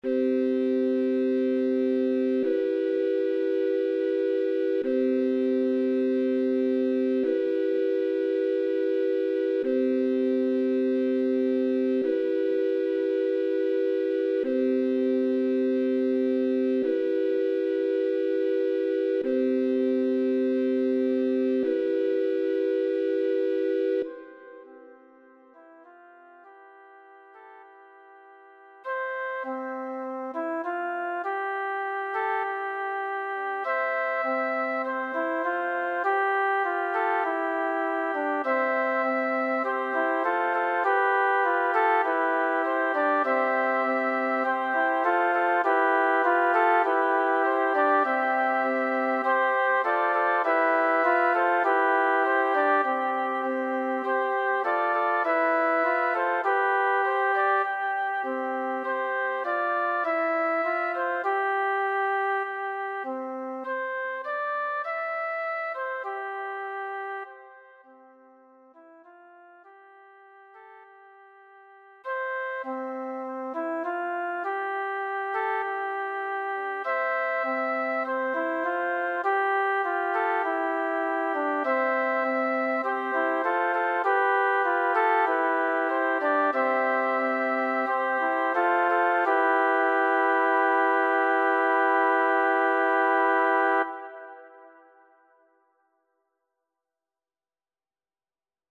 Ostinato